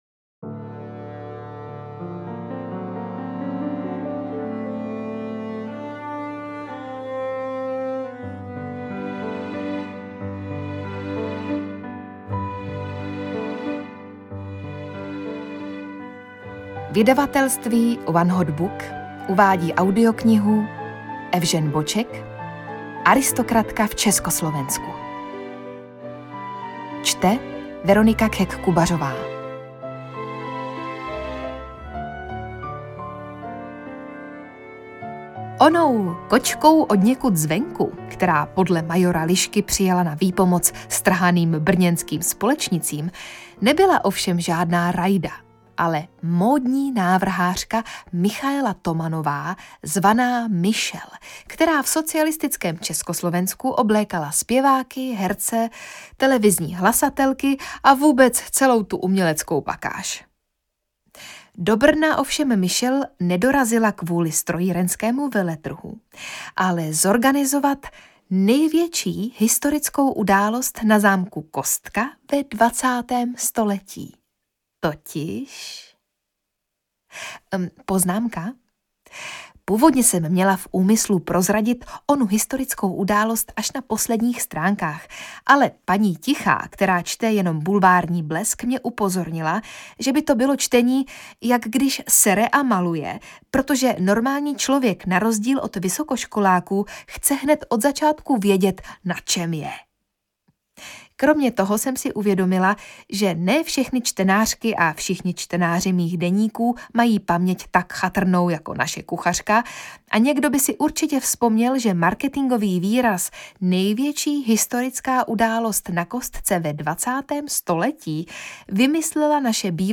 Audiobook
Read: Veronika Khek Kubařová